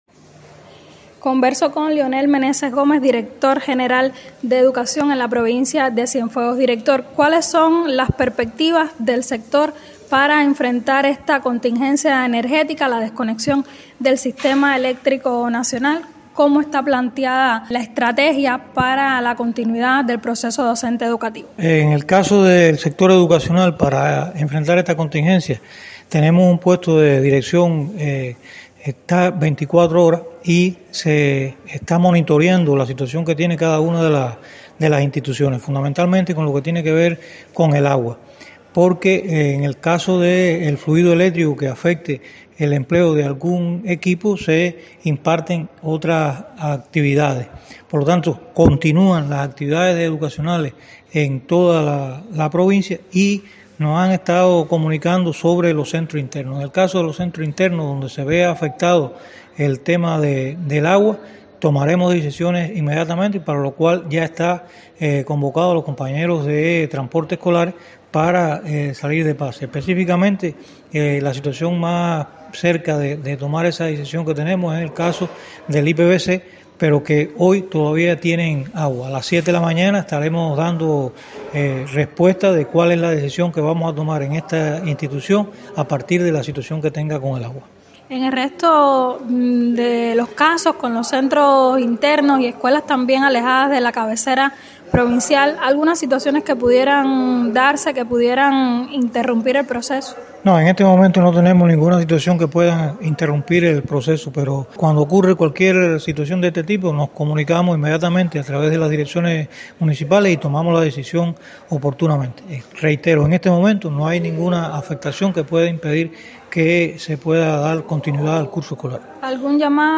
Declaraciones de Lionel Meneses, director general de Educación